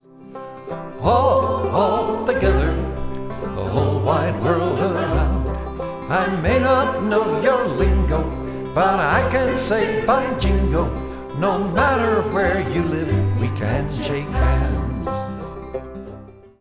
voice, banjo
bass
drums
chorus